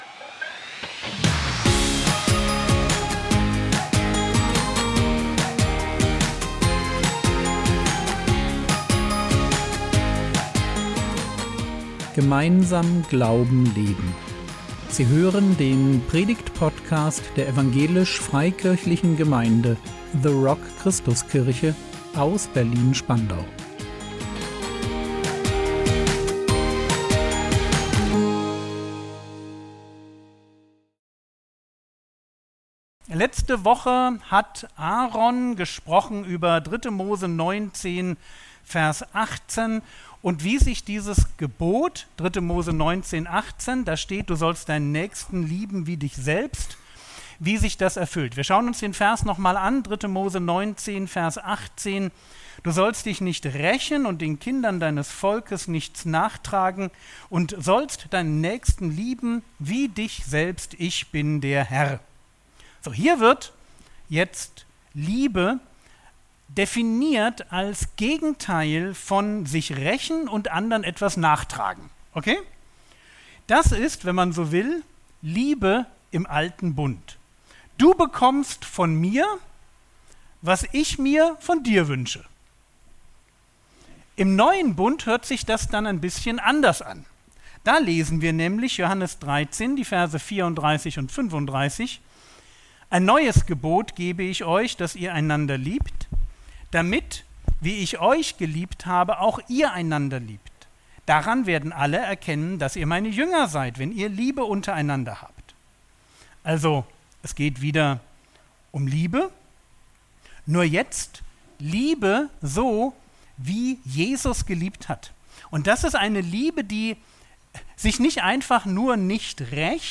Liebe leben im Neuen Bund (1) | 29.06.2025 ~ Predigt Podcast der EFG The Rock Christuskirche Berlin Podcast